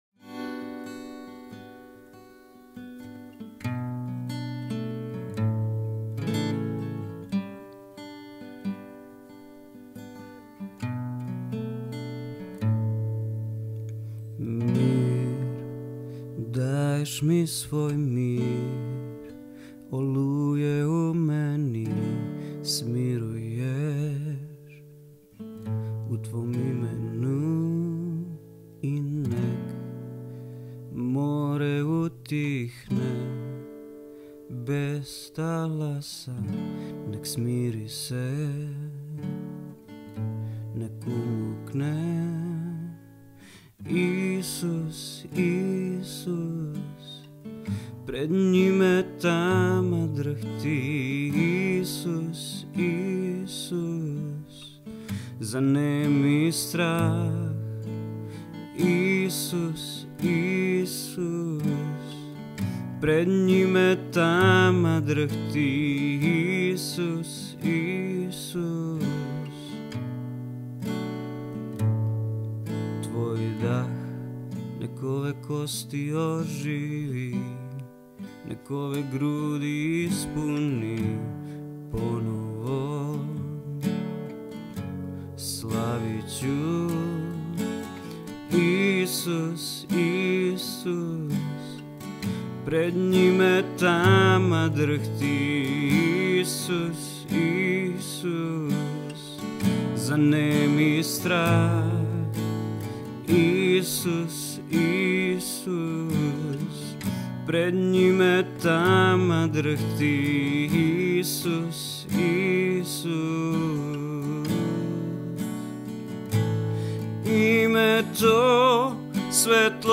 66 просмотров 71 прослушиваний 1 скачиваний BPM: 148